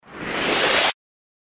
FX-922-WIPE
FX-922-WIPE.mp3